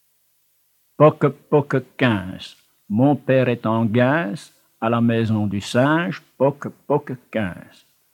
Genre : chant
Type : chanson à poter
Interprète(s) : Anonyme (homme)
Support : bande magnétique
"Comptine." (Note du collecteur)